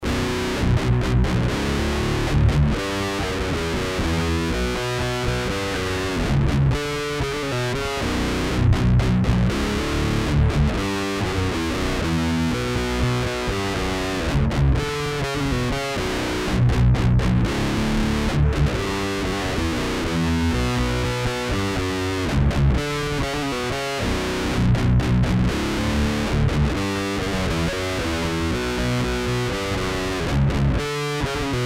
The chain was Jackson DK27->Randall RD100H (speaker out 16Ohms)->Torpedo Reload (Speaker In (16 Ohms) -> Loadbox out) -> E-MU 1820.
Dead, bassy, muffled sound...
What you hear is the sound taken from the loadbox output, with no WOS III applied...